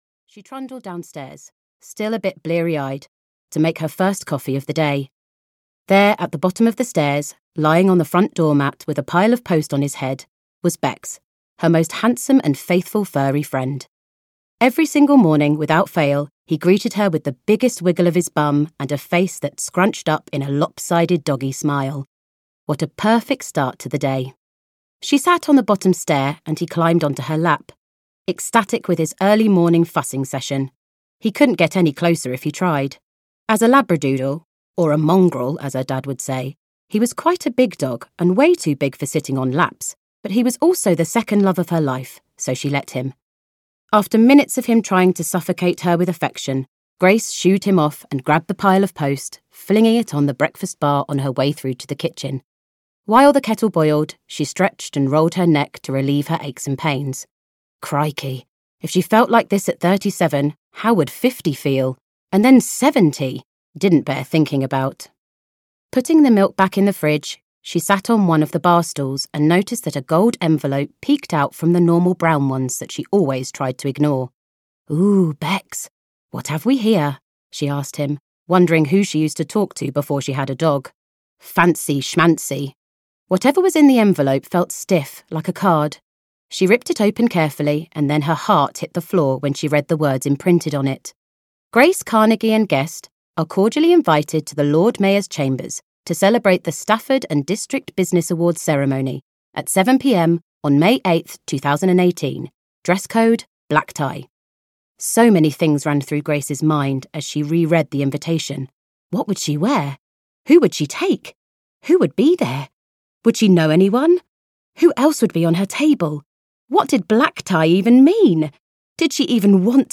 Amazing Grace (EN) audiokniha
Ukázka z knihy